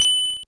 snd_bell_ch1.wav